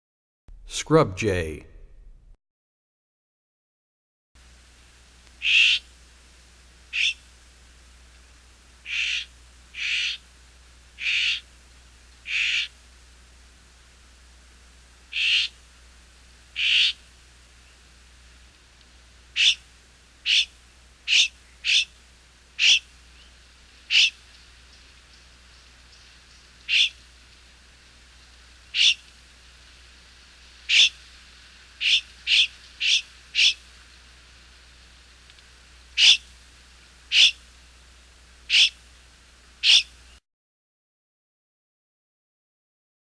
81 Scrub Jay.mp3